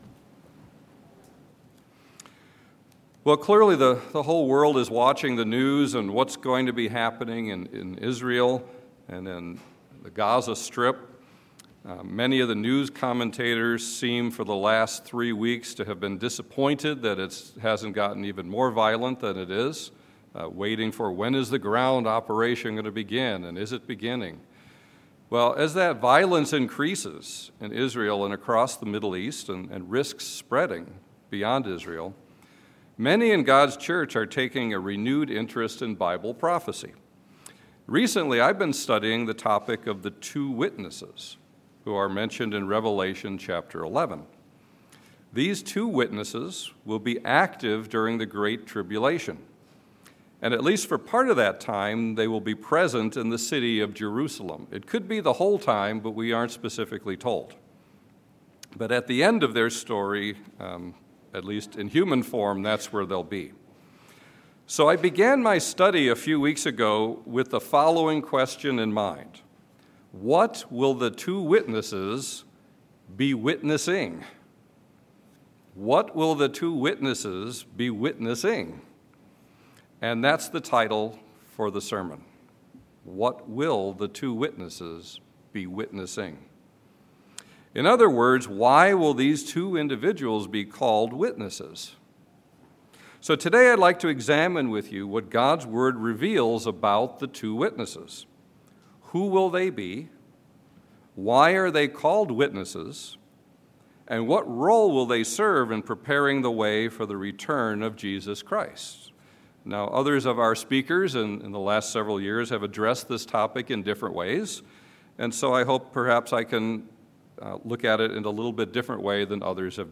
This sermon examines what God’s Word reveals about the Two Witnesses.